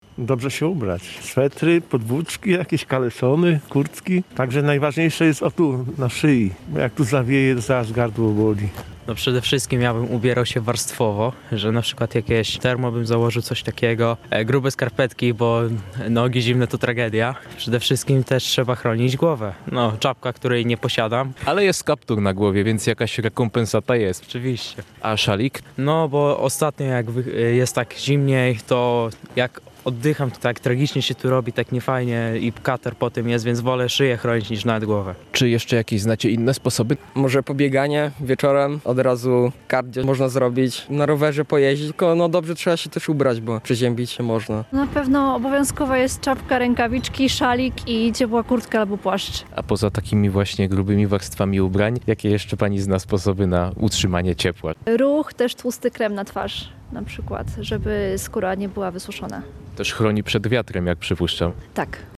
W związku z zapowiadanym ochłodzeniem zapytaliśmy mieszkańców Rzeszowa, jak najlepiej chronić się przed silnym mrozem i niskimi temperaturami.
Jak-chronic-sie-przed-zimnem-SONDA-1.mp3